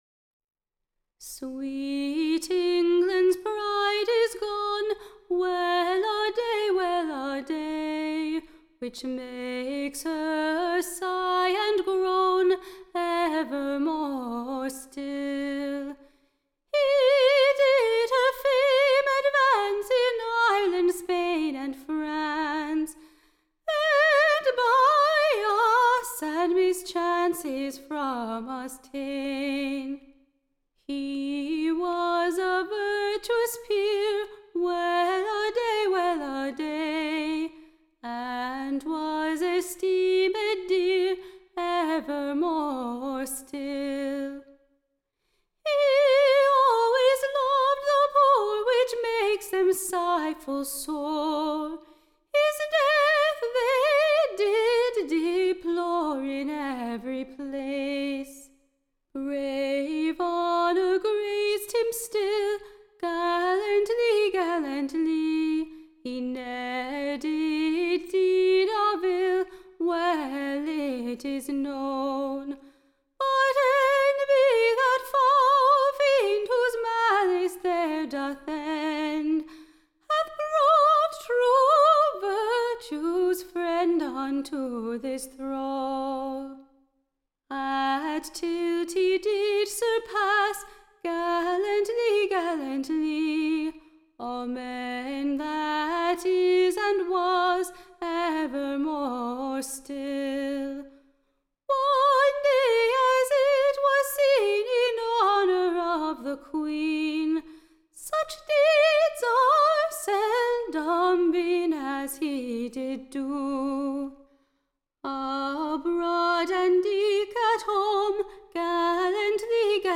Recording Information Ballad Title A lamentable Ditty composed vpon the Death of Robert Lord Devereux, late Earle of Essex, who was be- / headed in the Tower of London, on Ashwenesday in the morning, 1600. Tune Imprint To the tune of Welladay Standard Tune Title Welladay Media Listen 00 : 00 | 25 : 39 Download P2.162-3.mp3 (Right click, Save As)